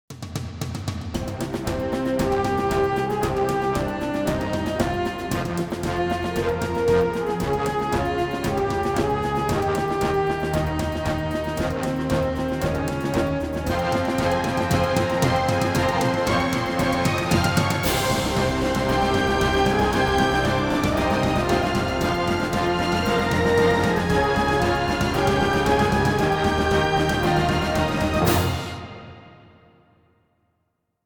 激情的背景音乐